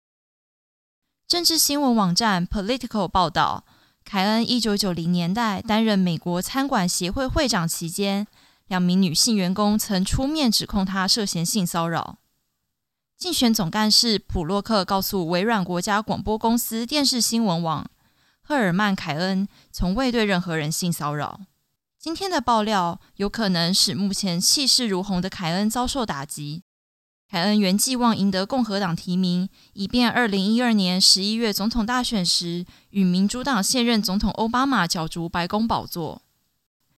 中国語ナレーター